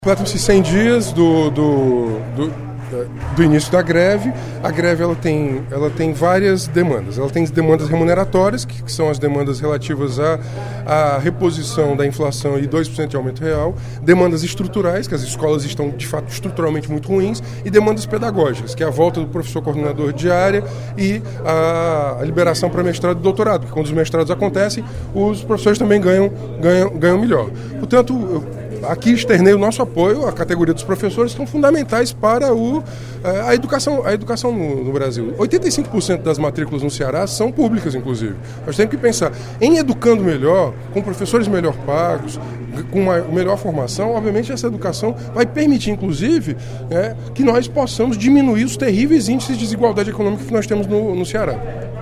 O deputado Renato Roseno (Psol) destacou, no primeiro expediente da sessão plenária desta terça-feira (02/08), os 100 dias da greve dos professores da rede pública do Ceará e lamentou a falta de uma resposta por parte do Governo Estadual para as demandas dos educadores.